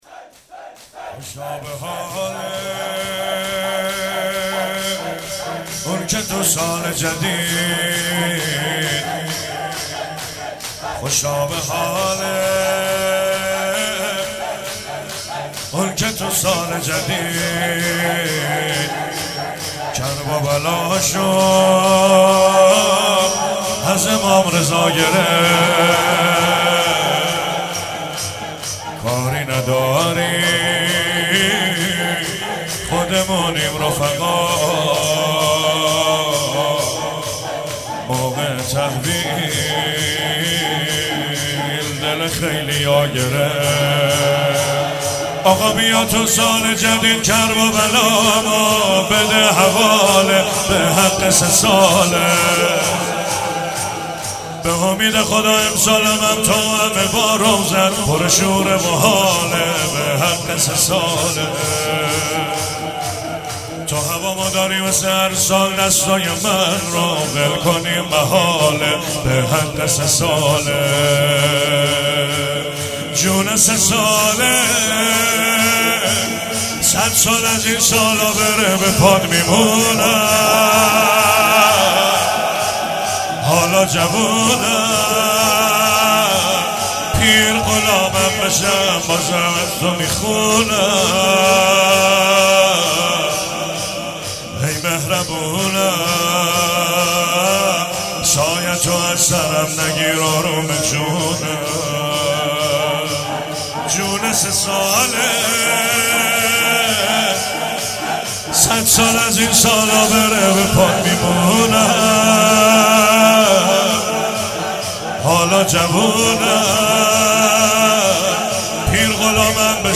اجرا شده در هیئت علمدار مشهد